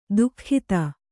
♪ duhkhita